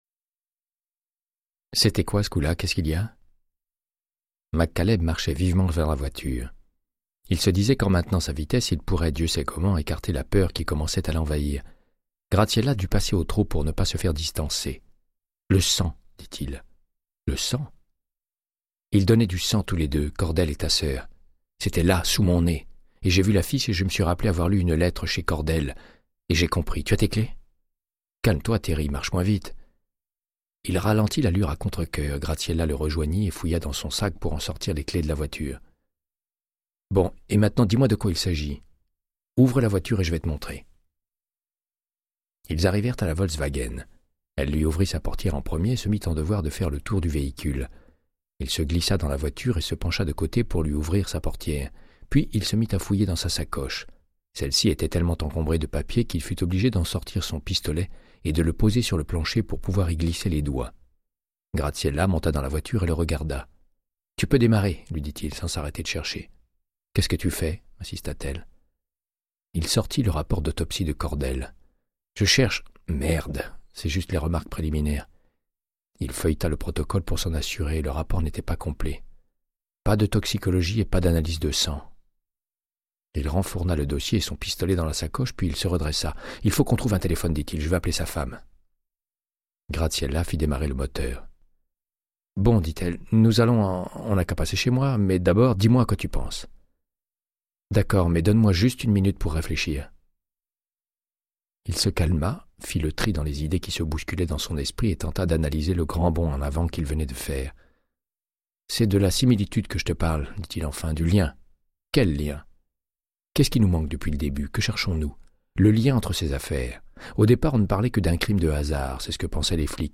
Audiobook = Créance de sang, de Michael Connelly - 112